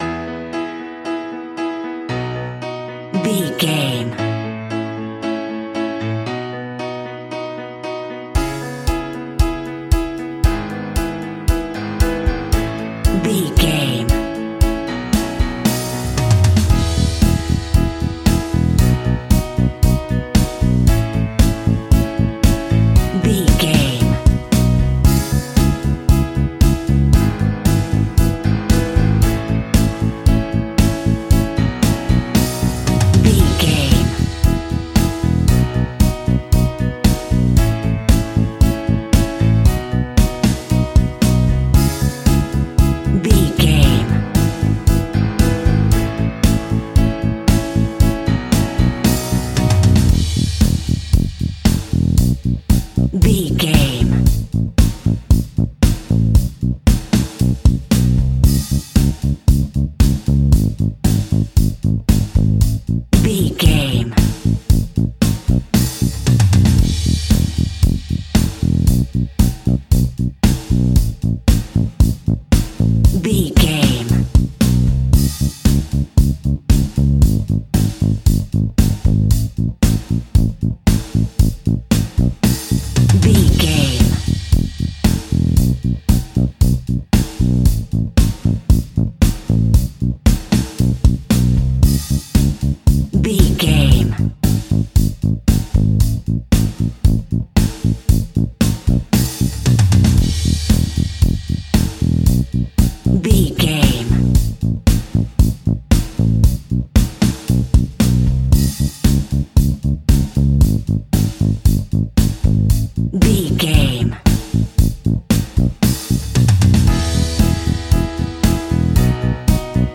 Epic / Action
Fast paced
Ionian/Major
pop rock
energetic
uplifting
acoustic guitars
drums
bass guitar
electric guitar
piano
organ